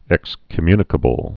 (ĕkskə-mynĭ-kə-bəl)